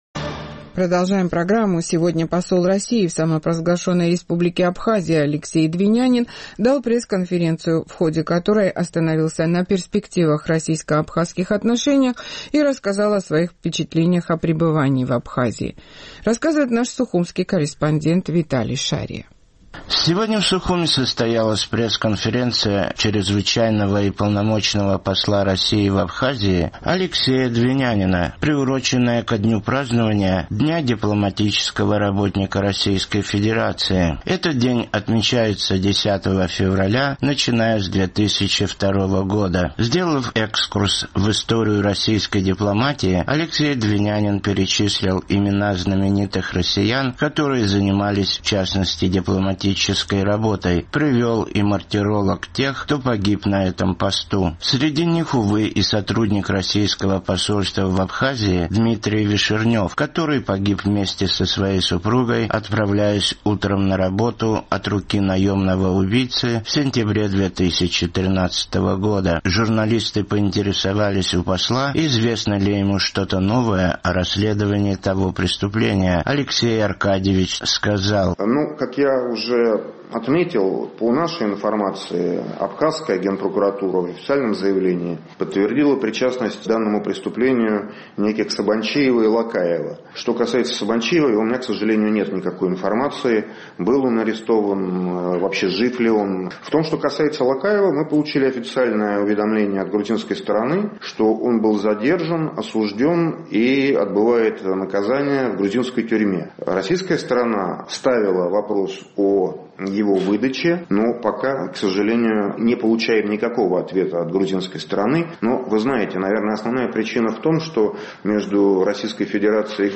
Сегодня посол России в Абхазии Алексей Двинянин дал пресс-конференцию, в ходе которой остановился на перспективах российско-абхазских отношений и впечатлениях о пребывании в Абхазии.